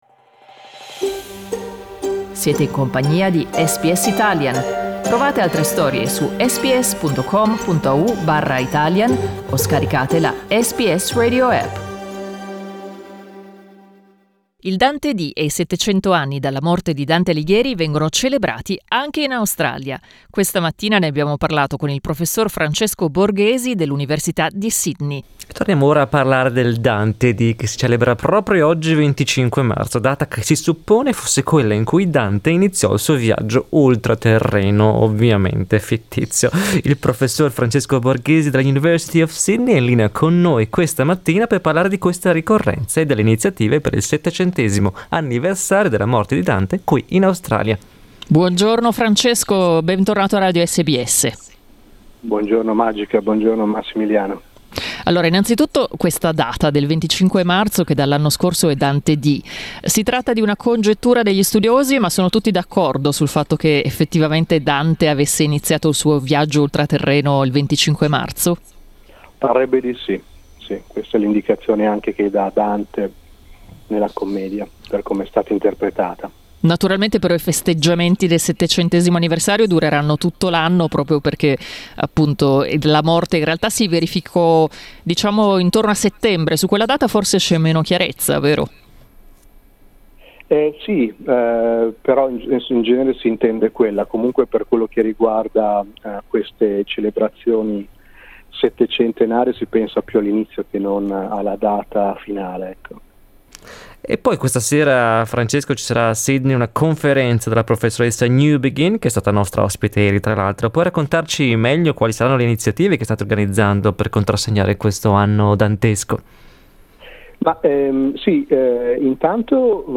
Ai microfoni di SBS Italian ha parlato di questa ricorrenza e delle iniziative per il 700mo anniversario della morte di Dante in Australia.